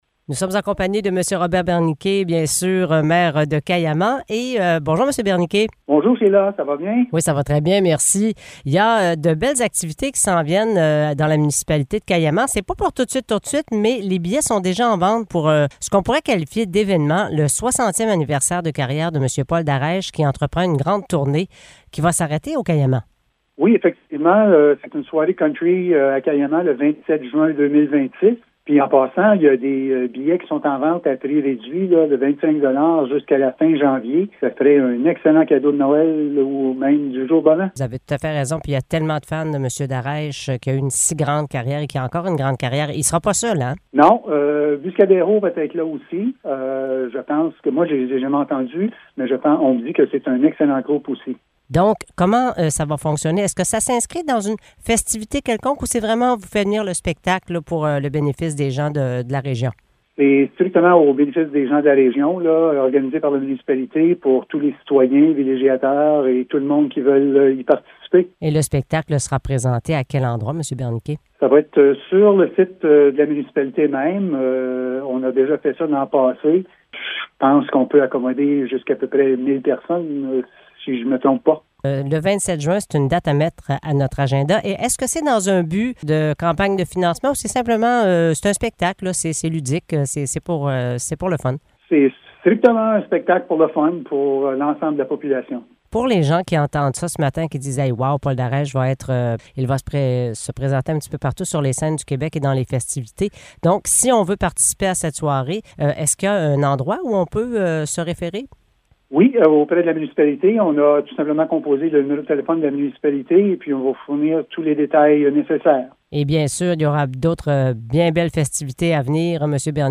Épisode Entrevue Carnet social